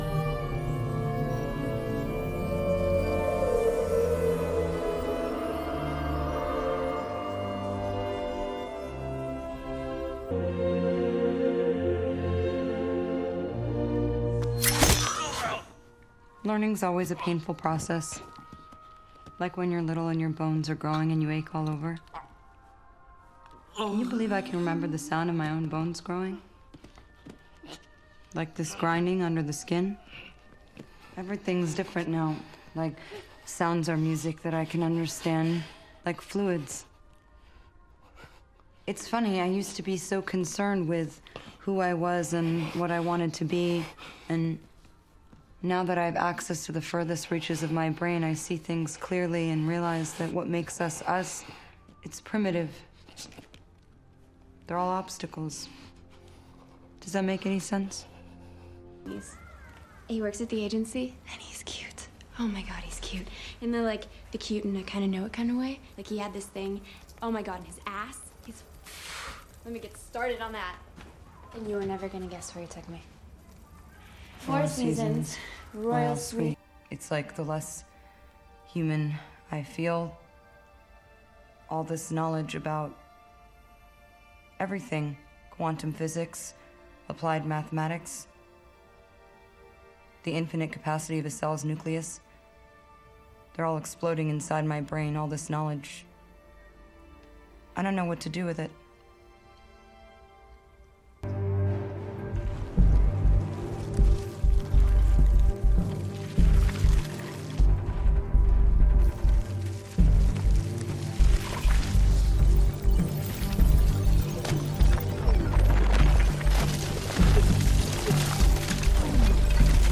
Anyway, there is (around 1:30 in this clip) "quantum physics" and "applied mathematics" muttered, justifying to put it into this "math and movies" collection.